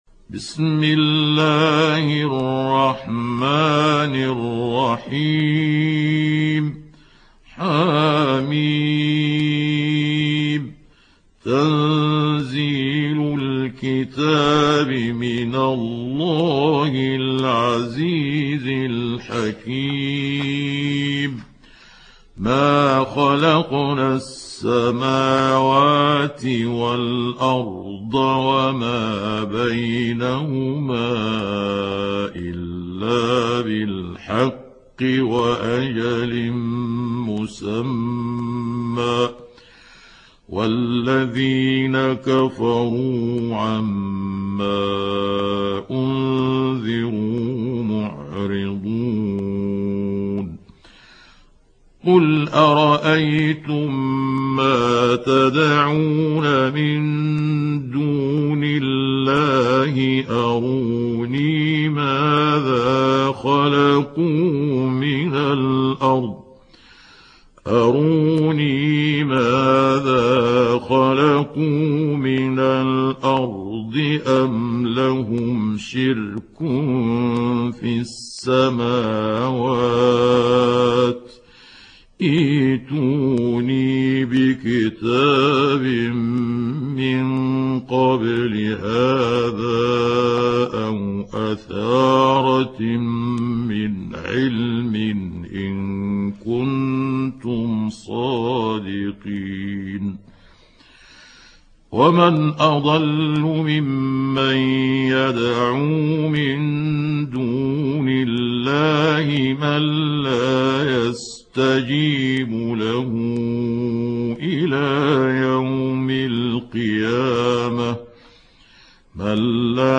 دانلود سوره الأحقاف mp3 محمود عبد الحكم روایت حفص از عاصم, قرآن را دانلود کنید و گوش کن mp3 ، لینک مستقیم کامل